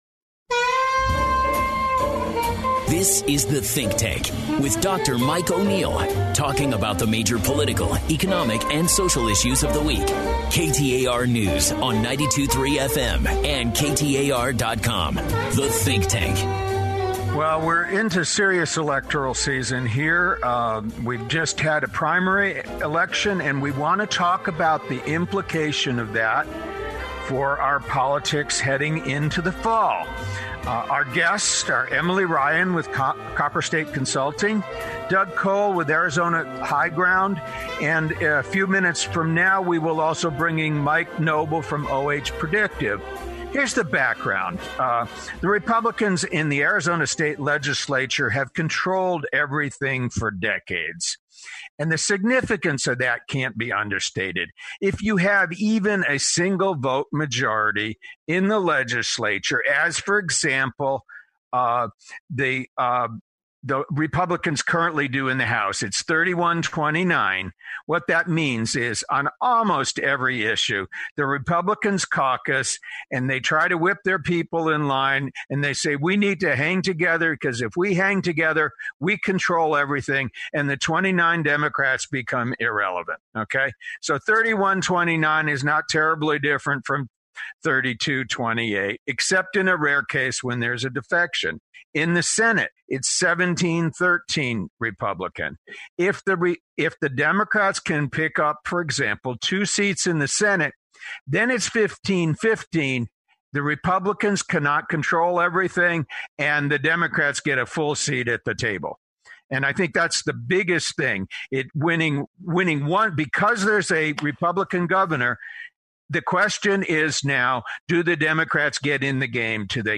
I listen via podcast often and I especially enjoyed his most recent episode in which he spoke with three Republican-leaning political consultants who all predicted Democratic victories in Arizona this general election.